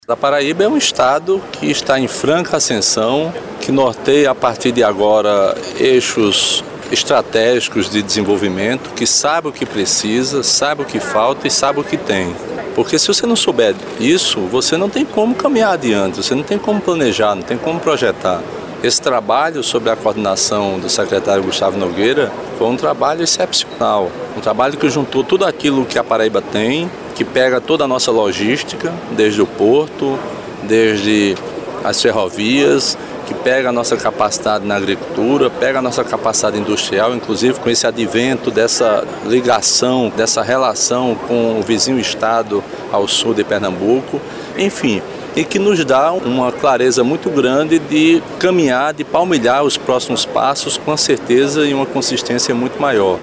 O evento aconteceu no Centro de Convenções Poeta Ronaldo Cunha Lima e contou com a participação dos secretários de Estado do Governo, representantes da iniciativa privada, universidades e público em geral.